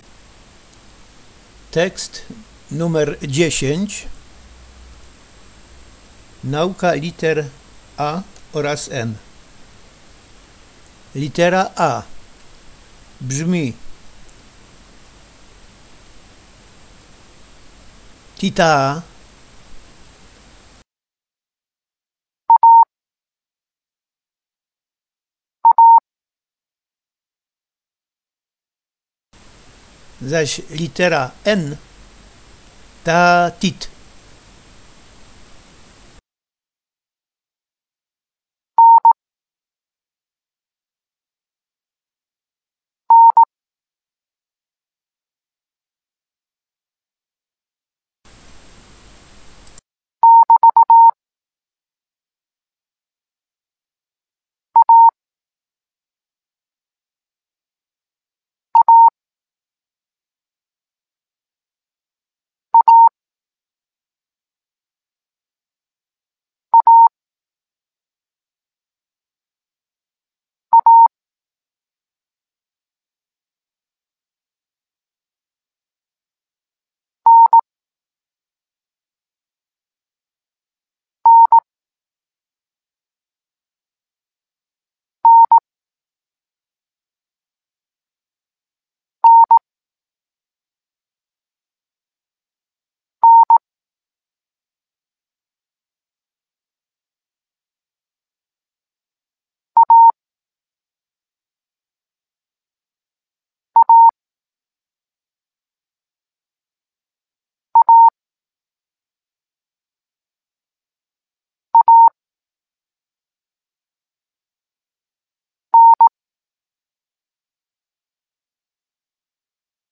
Tekst 10 – nauka liter A N
Melodia litery –  A  – ti-taaa
N  – taaa-tit